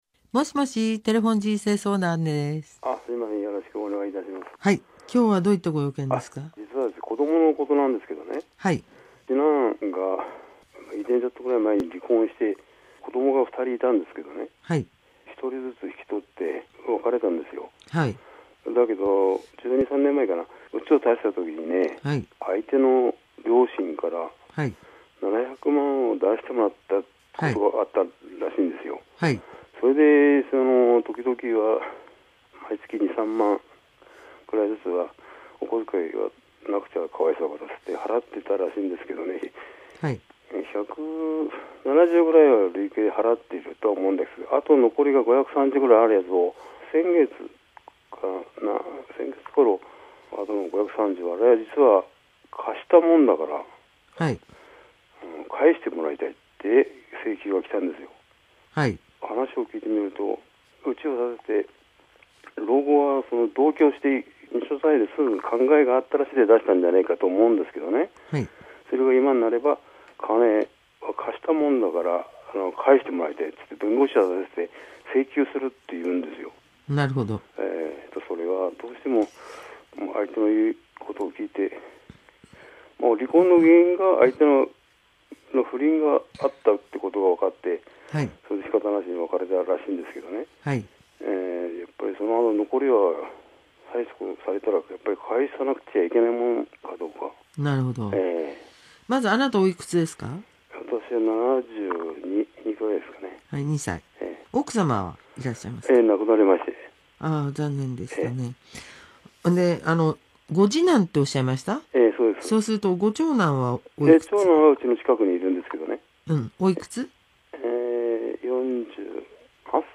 （内容ここまで） モゴモゴ、モゴモゴ、何言ってるのか分からん。